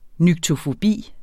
Udtale [ nygtofoˈbiˀ ]